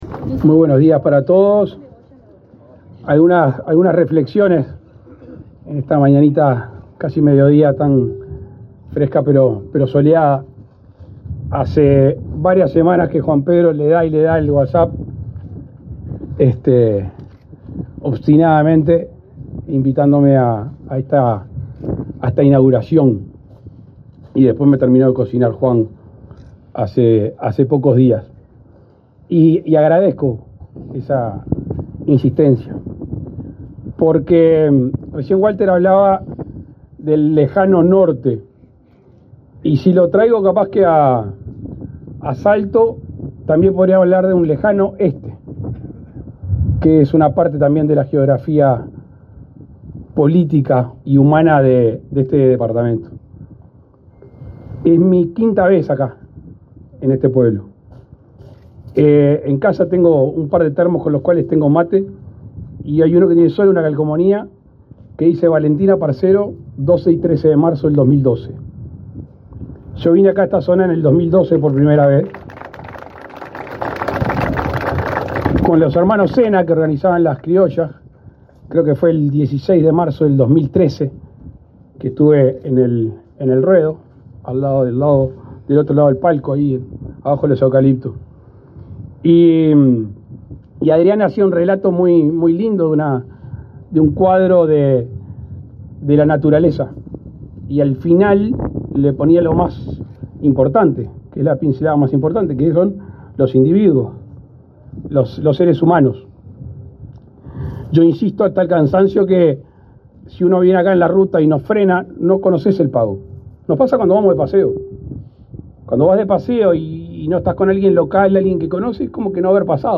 Palabras del presidente de la República, Luis Lacalle Pou
Palabras del presidente de la República, Luis Lacalle Pou 24/06/2024 Compartir Facebook X Copiar enlace WhatsApp LinkedIn El presidente de la República, Luis Lacalle Pou, participó, este 24 de junio, en la inauguración de un anexo de la escuela agraria en Rincón de Valentín, en el departamento de Salto.